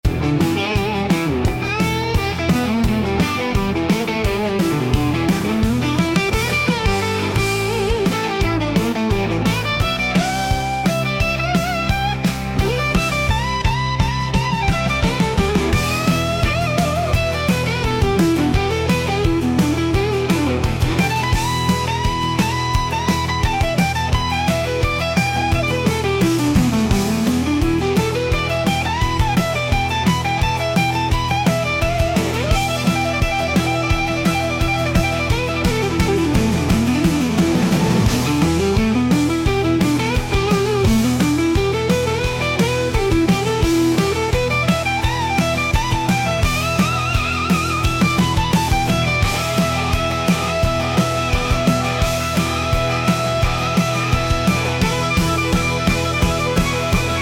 The music track reminded me very much of another song...... Can't quite place it.